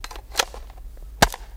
Loading Bullets | Sneak On The Lot